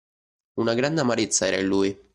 Read more he (disjunctive) him it Frequency A1 Hyphenated as lùi Pronounced as (IPA) /ˈluj/ Etymology Inherited from Late Latin illui.